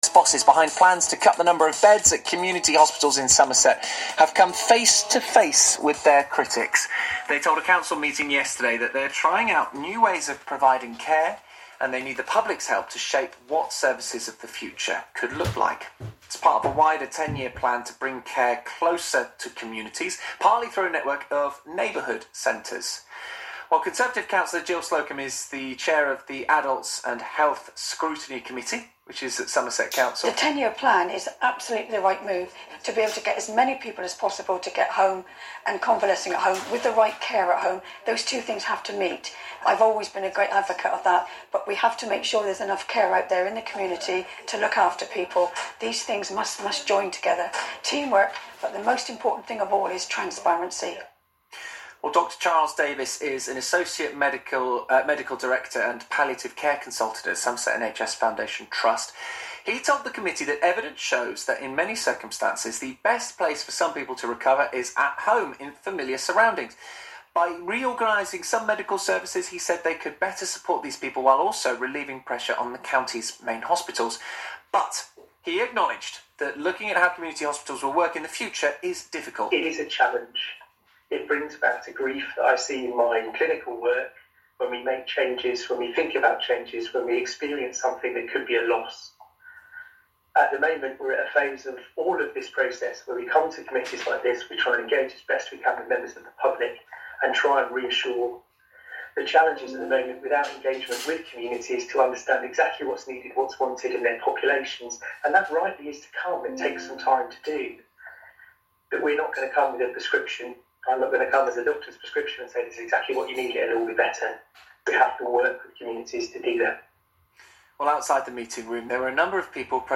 [Please note the external noises were not live on the radio, they are from my boisterous puppy as I captured the recording.]